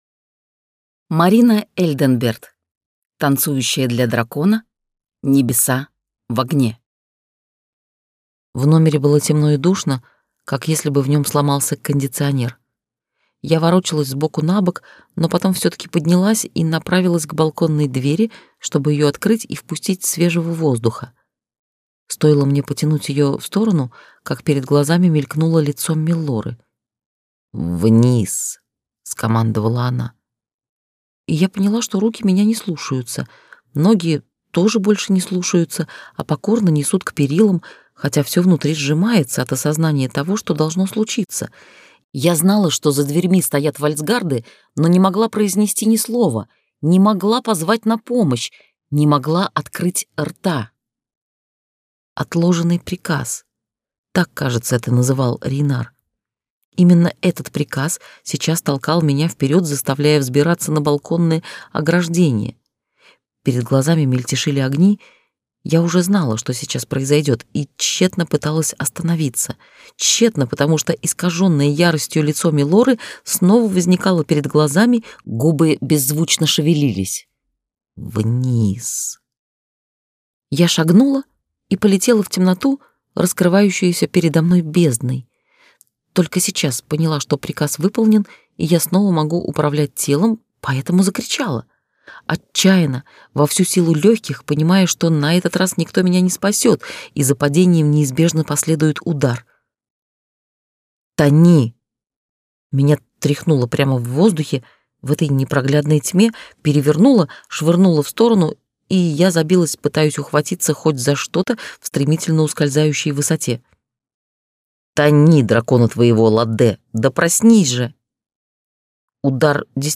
Аудиокнига Танцующая для дракона. Небеса в огне - купить, скачать и слушать онлайн | КнигоПоиск